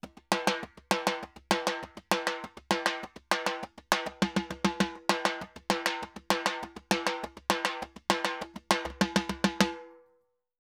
Timba_Samba 100_2.wav